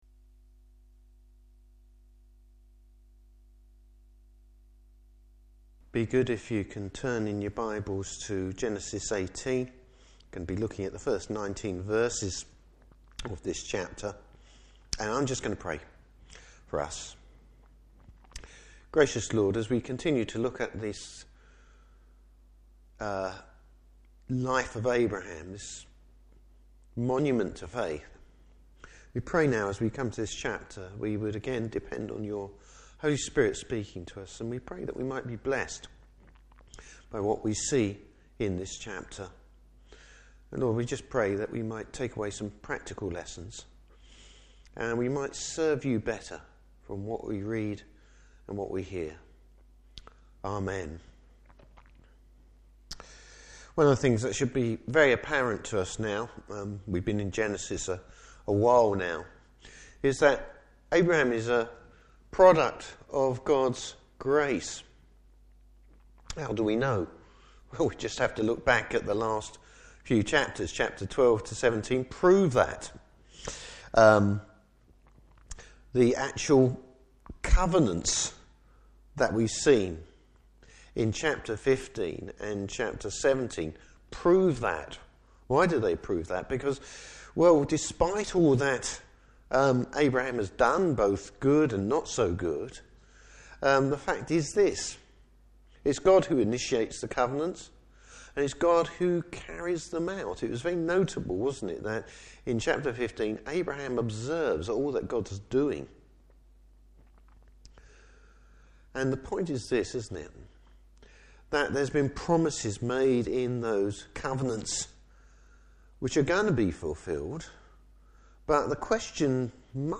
Service Type: Evening Service Abraham receives confirmation of the Lord’s blessing.